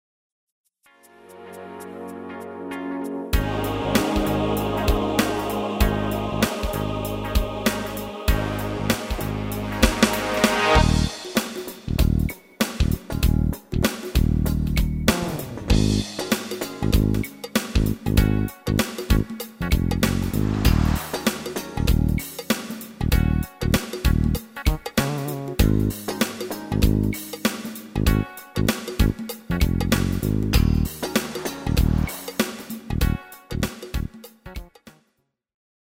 Demo/Koop midifile
Genre: Pop & Rock Internationaal
- GM = General Midi level 1
- Géén vocal harmony tracks
Demo's zijn eigen opnames van onze digitale arrangementen.